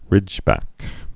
(rĭjbăk)